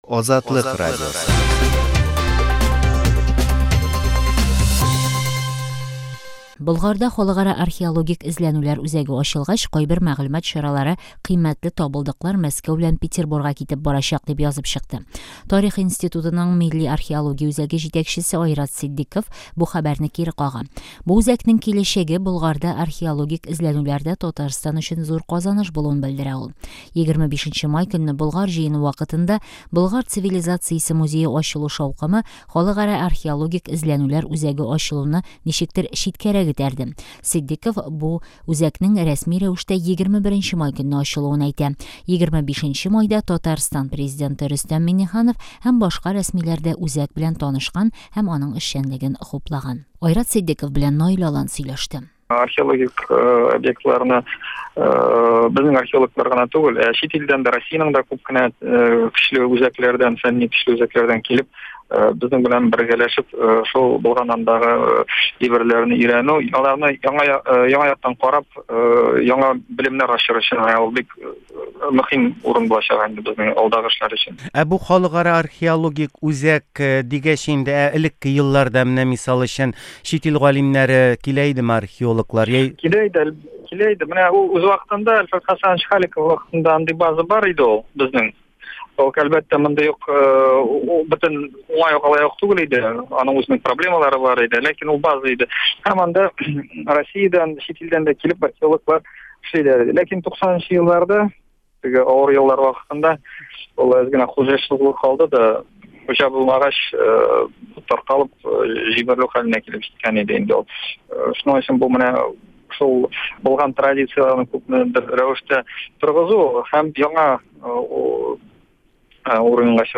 әңгәмә